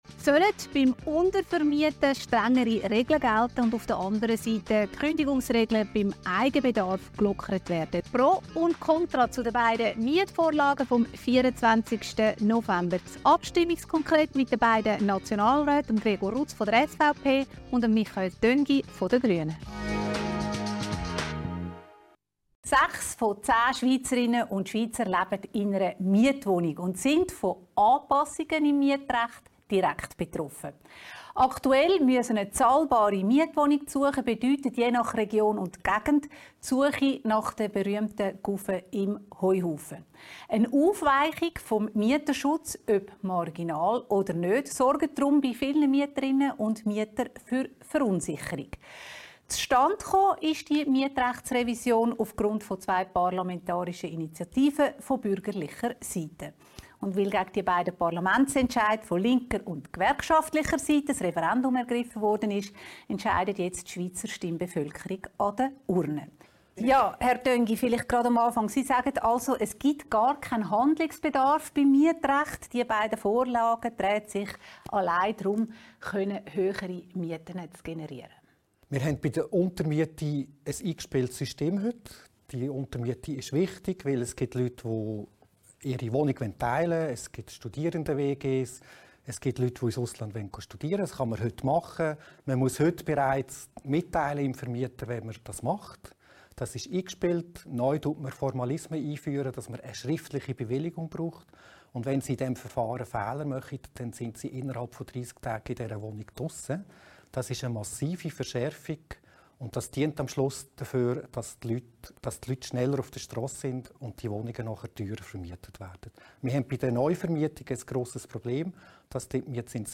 Das Abstimmungskonkret zu den beiden Mietvorlagen vom 24. November mit Gregor Rutz, Nationalrat SVP und Präsident Hauseigentümerverband Schweiz und Michael Töngi, Nationalrat Grüne und Vizepräsident Schweizer Mieterverband.